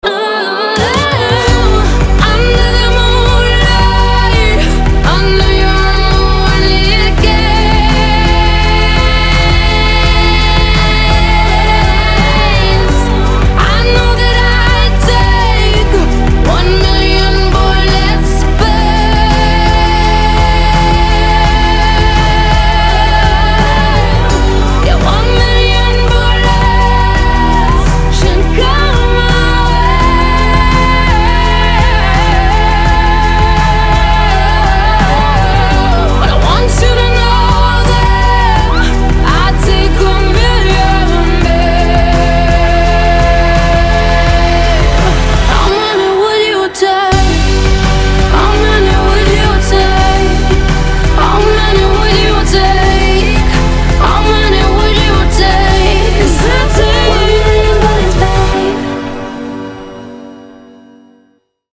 • Качество: 320, Stereo
поп
Blues